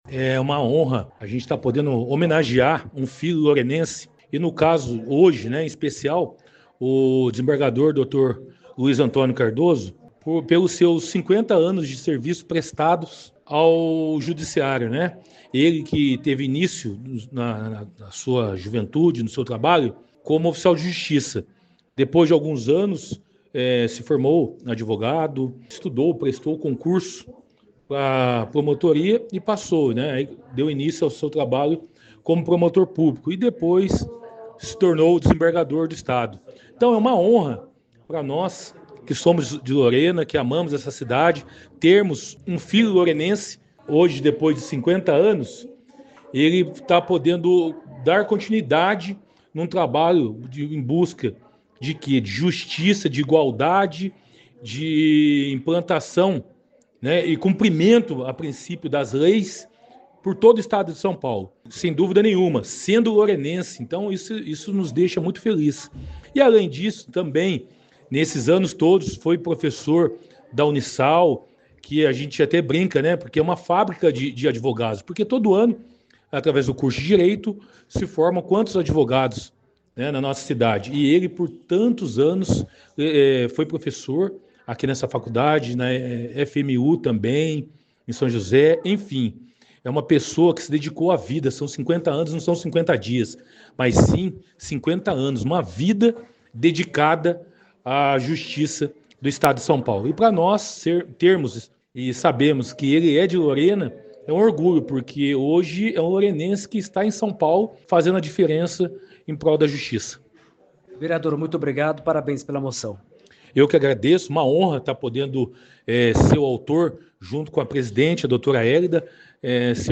Homenagem foi entregue, na sessão desta segunda-feira (5), no plenário do Legislativo
Áudio do vereador Carlos Alberto Pereira (Beto Pereira – PSD)